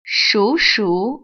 발음 : [ shúshú ]